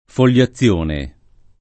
vai all'elenco alfabetico delle voci ingrandisci il carattere 100% rimpicciolisci il carattere stampa invia tramite posta elettronica codividi su Facebook foliazione [ fol L a ZZL1 ne ] o fogliazione [ fol’l’a ZZL1 ne ] s. f.